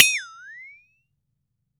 FLEXATONE  6.WAV